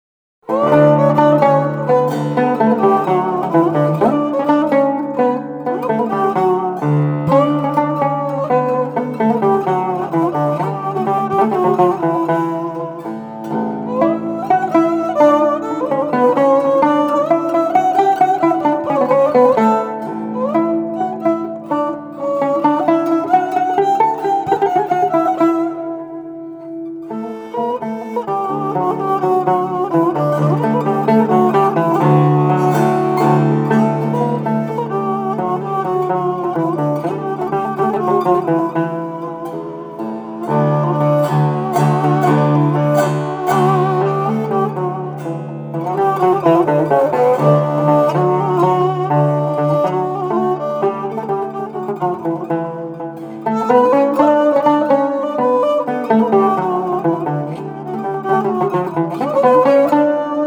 Genre: Turkish & Ottoman Classical.
Studio: Aria, Üsküdar, Istanbul